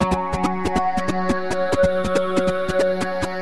多样化的 " 突兀的鼓点
Tag: 出问题 节奏 毛刺 节拍 鼓节拍